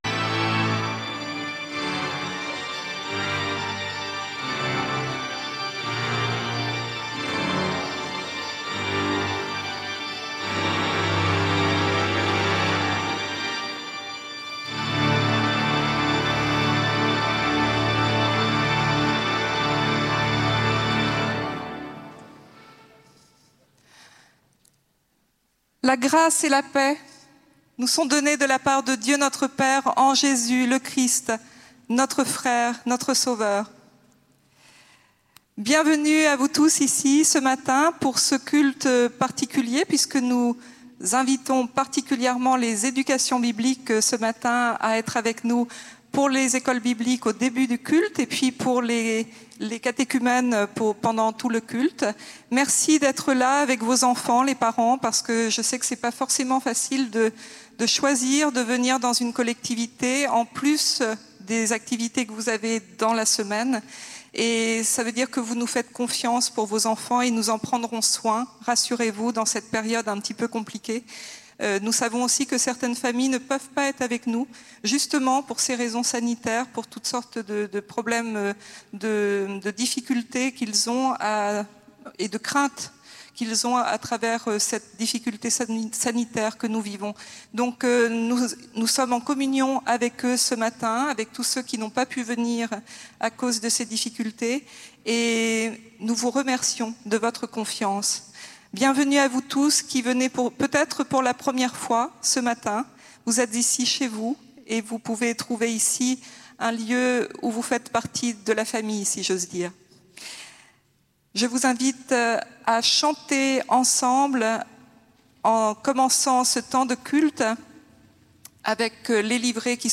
Écouter le culte entier (Télécharger au format MP3)